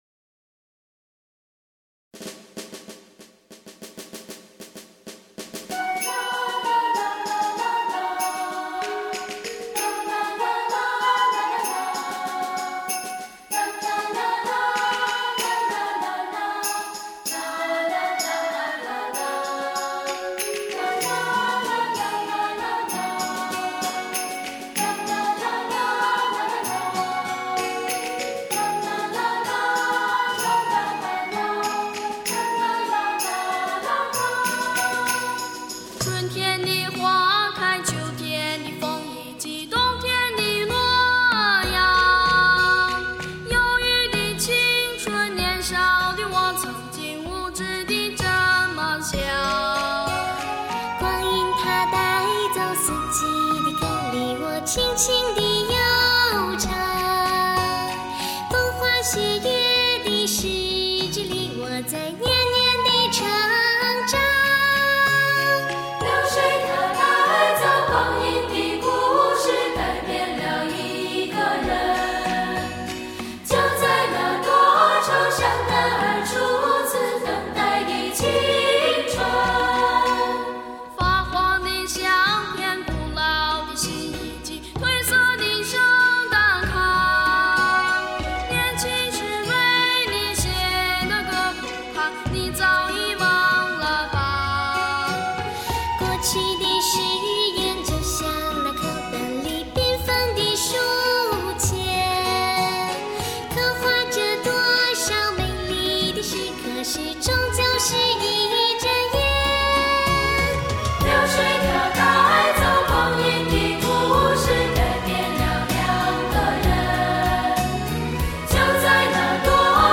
本专辑收录多首经典教会圣诗以及令人怀念的民歌；幸福温暖的合声，满足喜爱音乐的耳朵，更温暖您的心，温暖整个世界的心。
以清澈优美的和声，征服国内外乐迷